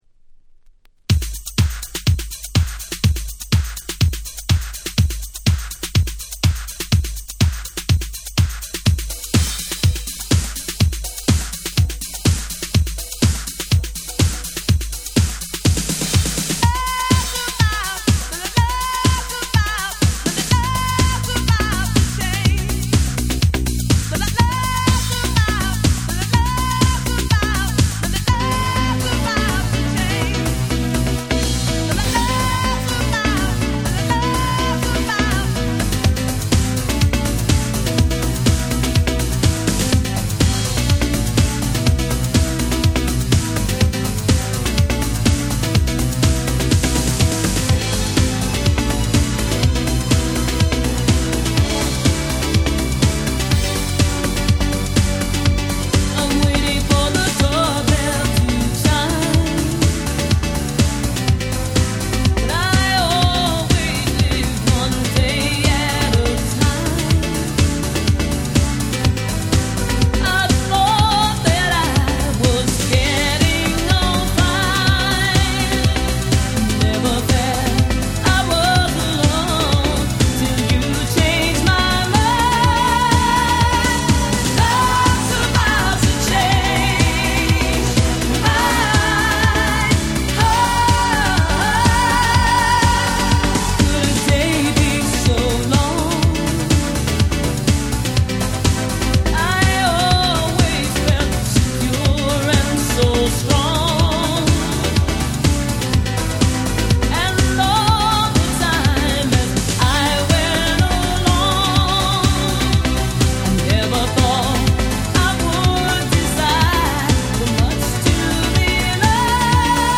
89' Smash Hit R&B / Disco / Eurobeat !!